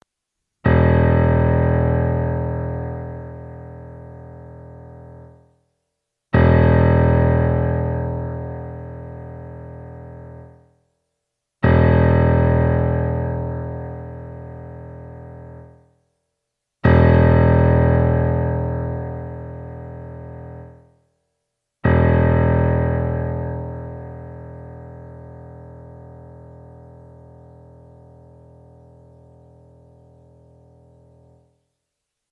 Referenzbeispiele zum Stimmen der E-, A-, D-, G- Kontrabasssaite
Wenn Sie auf die folgenden Links klicken, hören Sie, wie die Saiten klingen und können ihren Double Bass danach online stimmen:
E-Saite (mp3):
kontrabass_e.mp3